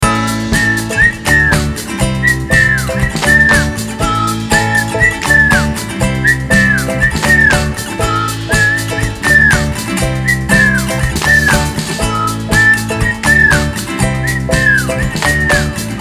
Instrumental Ringtones